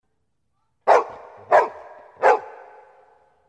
Thể loại nhạc chuông: Nhạc tin nhắn